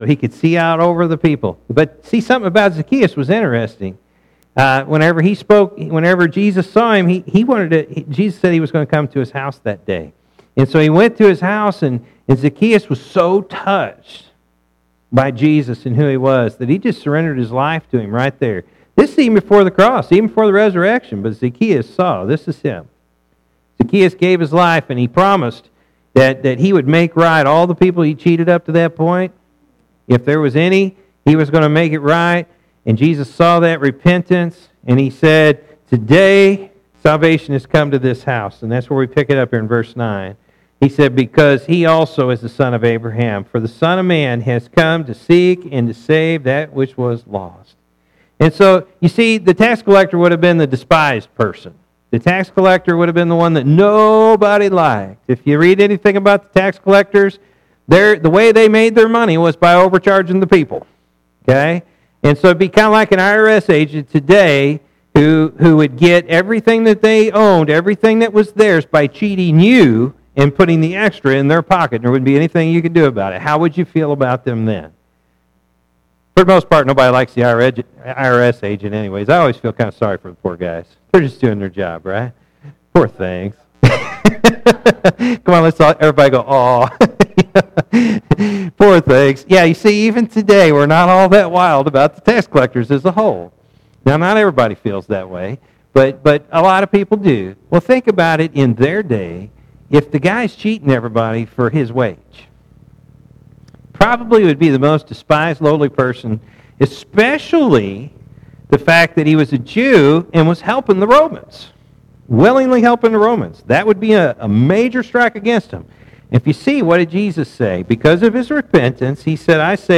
Dec-3-2017-morning-service.mp3